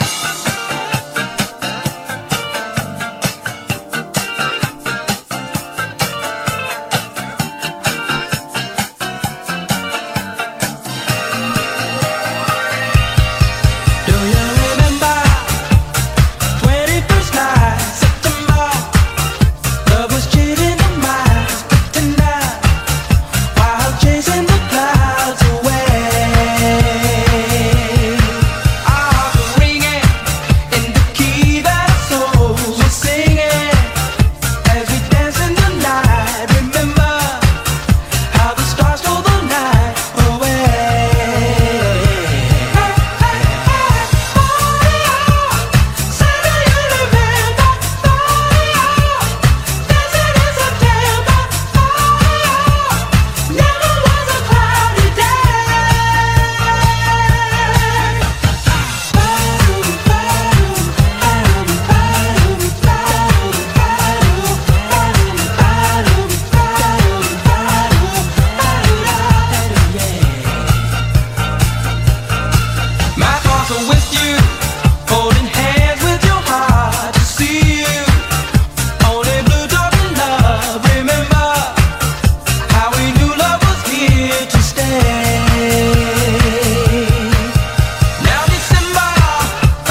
BREAKBEATS/HOUSE / JAPANESE CLUB (JPN)